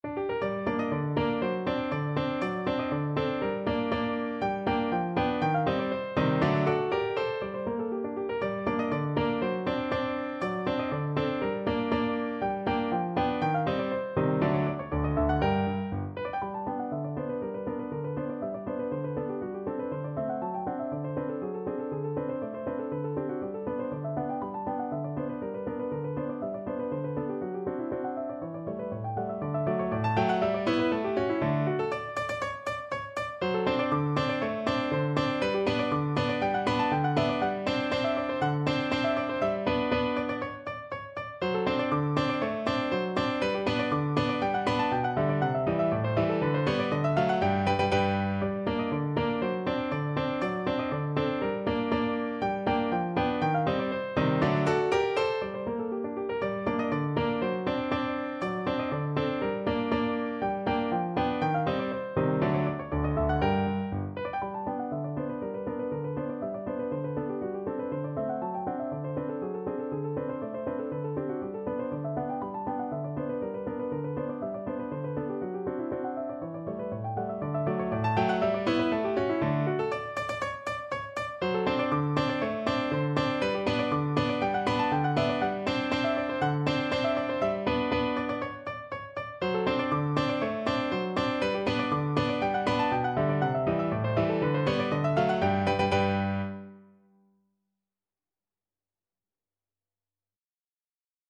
No parts available for this pieces as it is for solo piano.
2/4 (View more 2/4 Music)
Piano  (View more Advanced Piano Music)
Classical (View more Classical Piano Music)